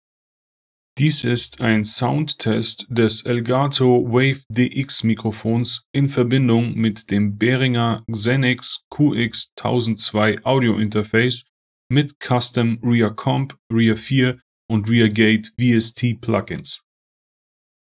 Soundtest
Softwareseitig ist die Lautstärke auf 60 % eingestellt und die Aufnahmedistanz beträgt etwa 20 Zentimeter.
Test 6: Behringer XENYX QX1002 mit VST PLUGINS